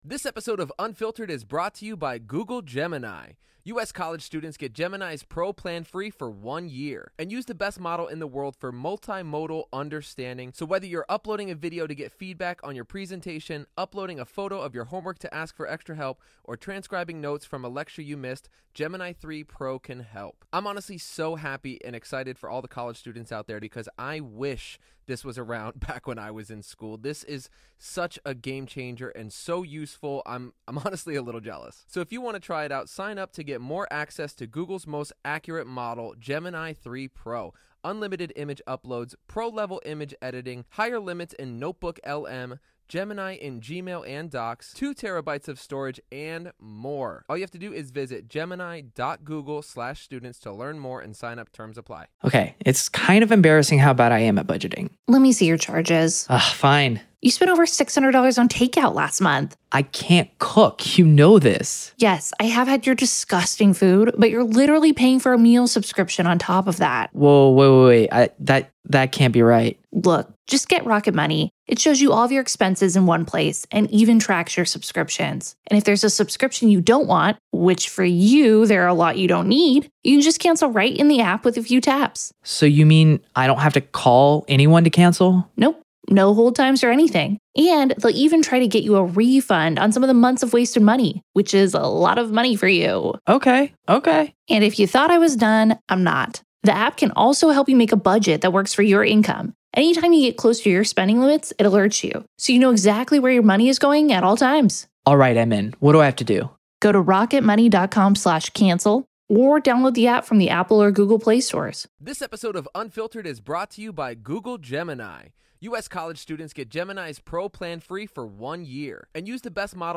LIVE COURTROOM COVERAGE — NO COMMENTARY
This series provides unfiltered access to the testimony, exhibits, expert witnesses, and courtroom decisions as they happen. There is no editorializing, no added narration, and no commentary — just the court, the attorneys, the witnesses, and the judge.